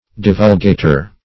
Divulgater \Div"ul*ga`ter\, n.